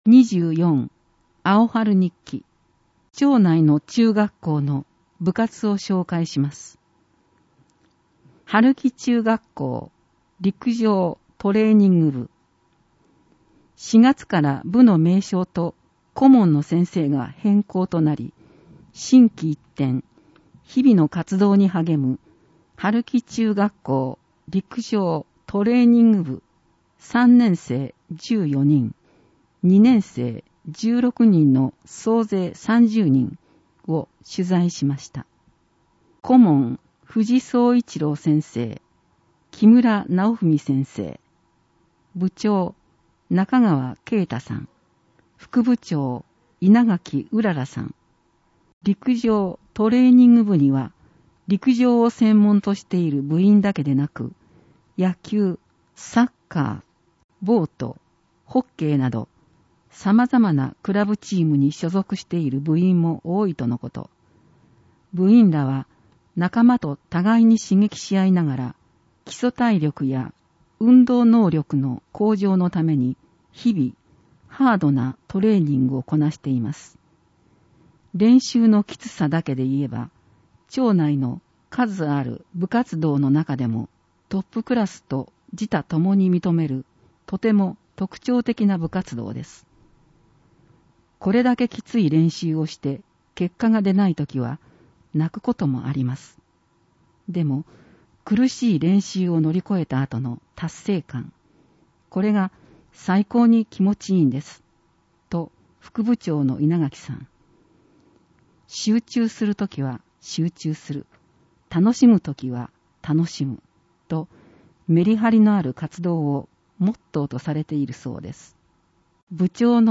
広報とうごう音訳版（2021年6月号）